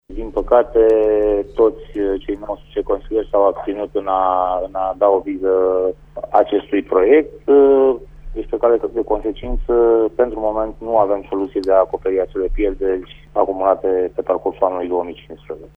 Furnizorul de energie termică Tetkron va înceta furnizarea de apă caldă către toți brașovenii racordați în sistem centralizat, în urma ședinței de azi a Consiliului Local Brașov. 19 consilieri locali s-au abținut în momentul în care s-a propus acoperirea datoriilor Tetkron către furnizorul de gaz, după cum ne spune viceprimarul Brașovului, Ciprian Bucur: